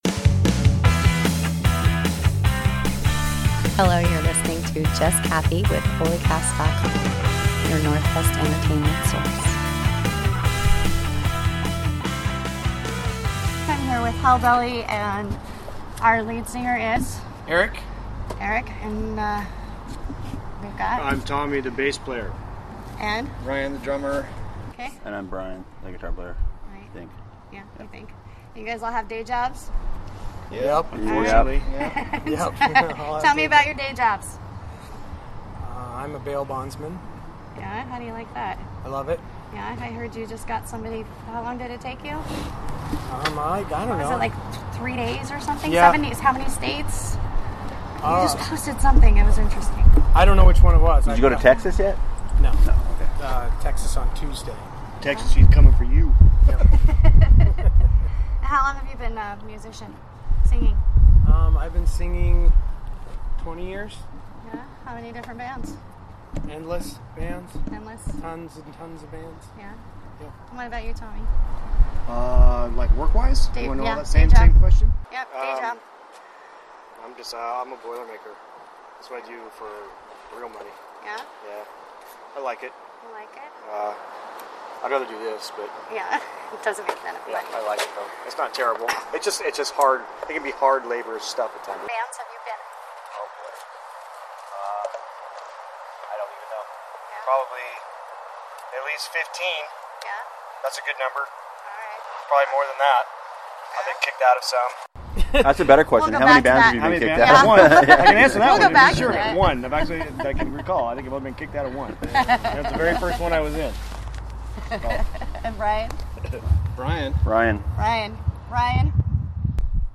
Vocals
Guitar
Drums
Bass